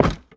Minecraft Version Minecraft Version latest Latest Release | Latest Snapshot latest / assets / minecraft / sounds / entity / armorstand / break2.ogg Compare With Compare With Latest Release | Latest Snapshot